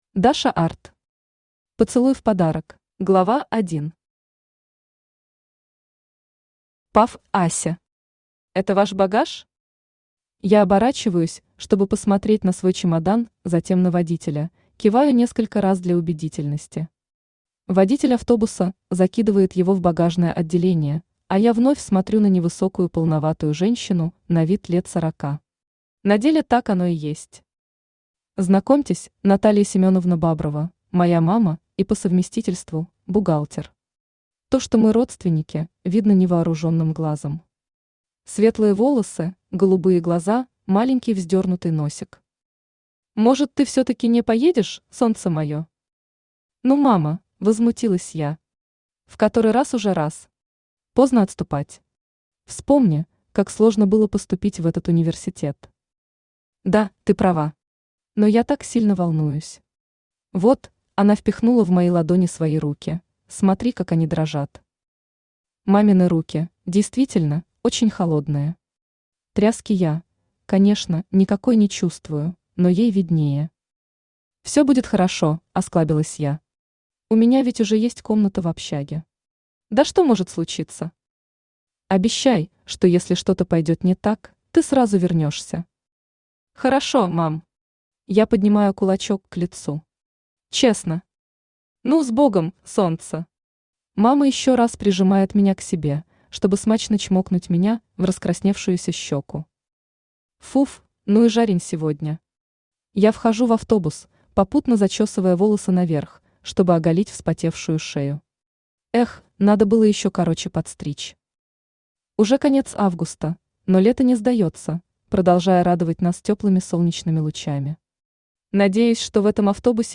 Аудиокнига Поцелуй в подарок | Библиотека аудиокниг
Aудиокнига Поцелуй в подарок Автор Даша Art Читает аудиокнигу Авточтец ЛитРес.